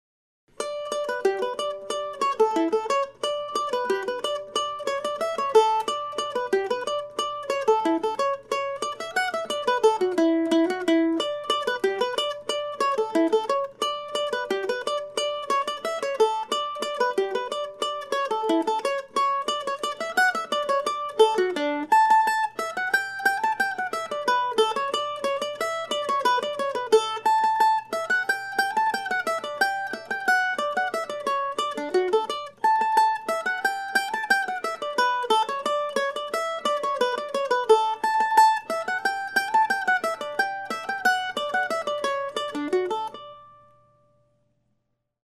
As stated in my previous post, these are short pieces modeled after James Oswald's 18th century divertimentos for "guittar" and titled after some of my favorite places here in the Decorah area.
I'll be playing all ten of these Postcards tomorrow night at Java John's Coffee House, along with music by James Oswald and others, from 7:00-9:00 p.m. Drop by if you would like to hear some solo mandolin music.